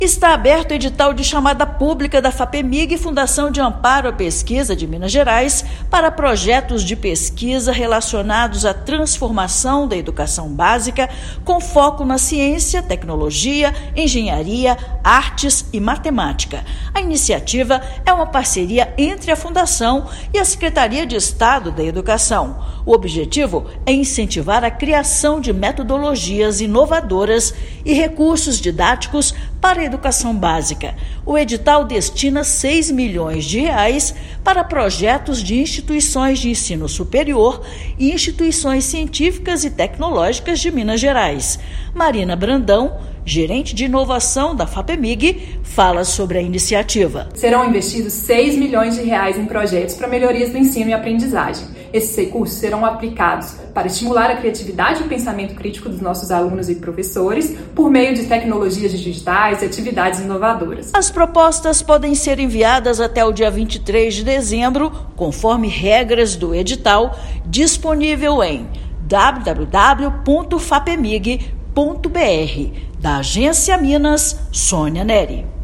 Iniciativa entre Fundação de Amparo à Pesquisa do Estado de Minas Gerais (Fapemig) e Secretaria de Estado de Educação de Minas Gerais (SEE/MG) fomenta parceria entre universidades e escolas estaduais, promovendo inovação científica e tecnológica. Submissão de propostas vai até 23/12. Ouça matéria de rádio.